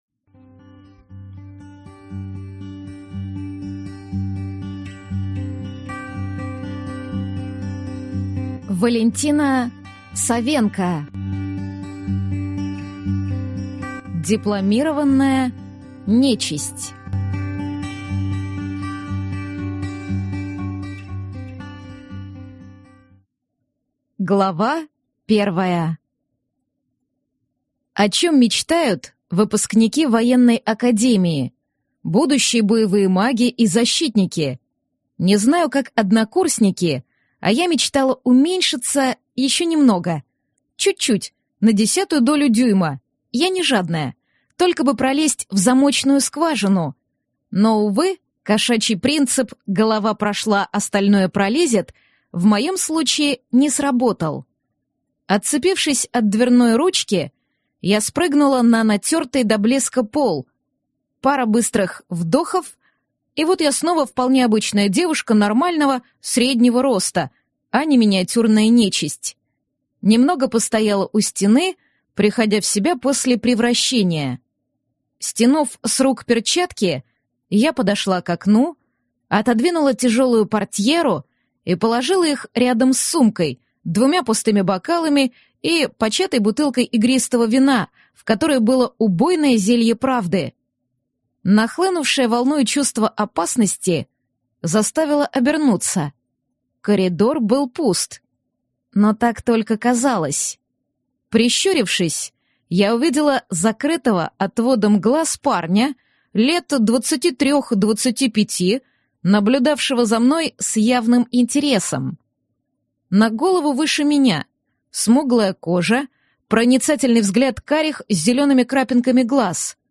Аудиокнига Дипломированная нечисть | Библиотека аудиокниг